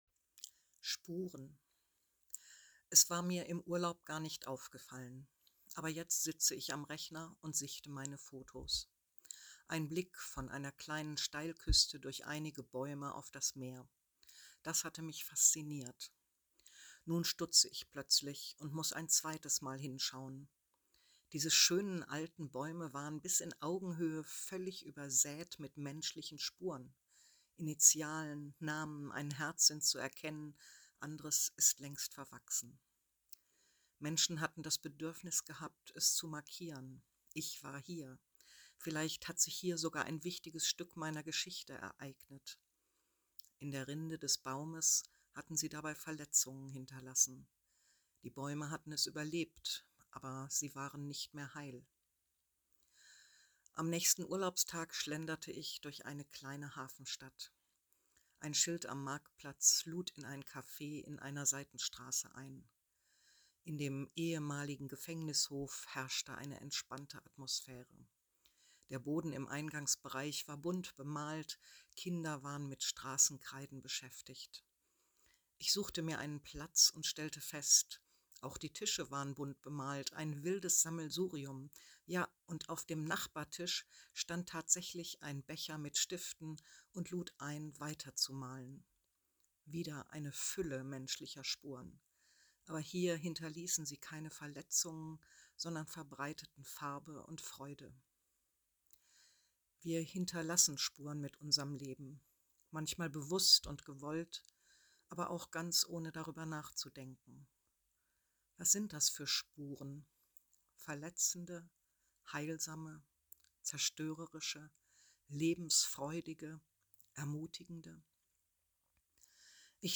Andacht 250827 spuren – EFG Hannover-Walderseestraße